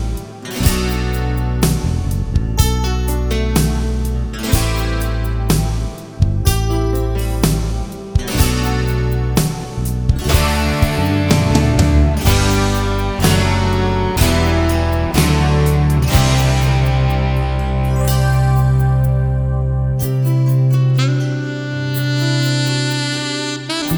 For Duet Pop (1980s) 4:05 Buy £1.50